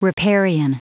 Prononciation du mot riparian en anglais (fichier audio)
Prononciation du mot : riparian